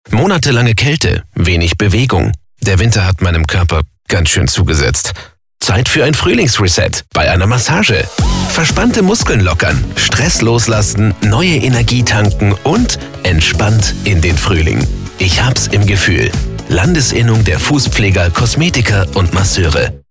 massage-radiospot.mp3